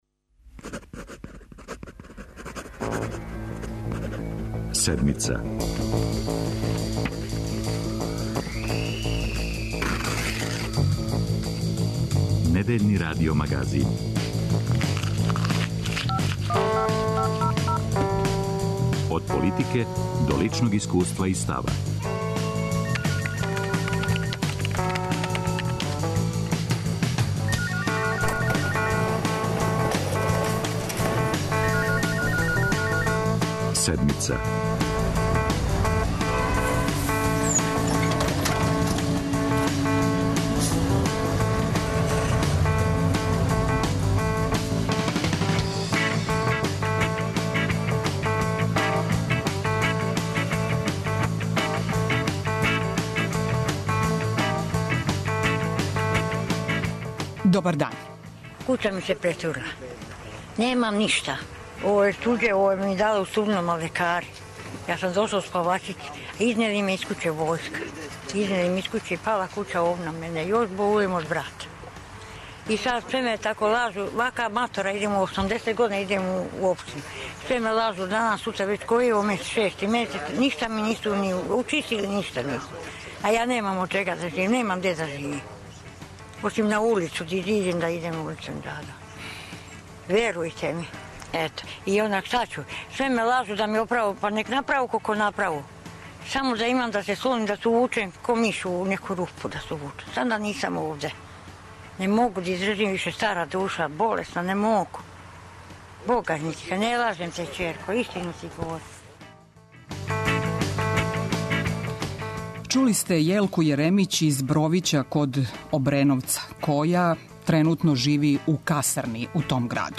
Гост емисије је директор Канцеларије за обнову Марко Благојевић.